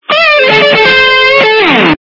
При прослушивании Windows Mobile - Roaringguitar качество понижено и присутствуют гудки.